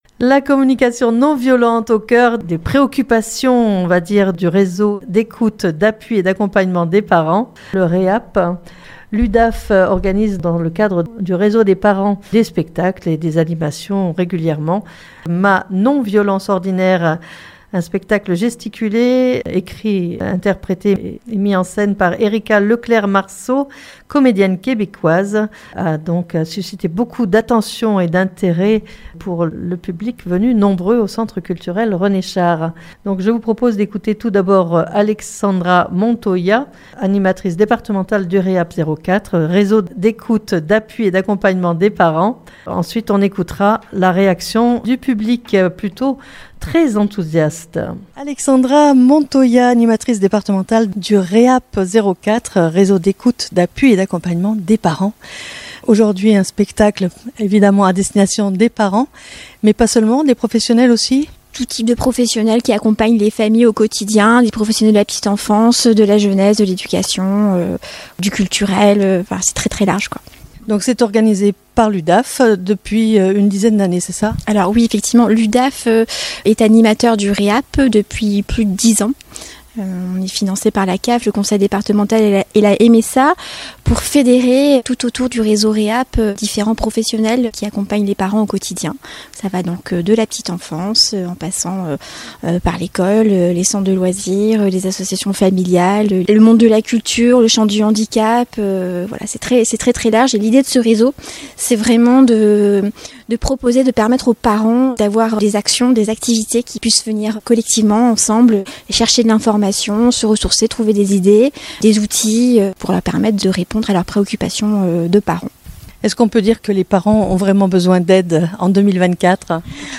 puis vous entendrez les réactions du public à l'issue du spectacle sous la forme d'un micro-trottoir.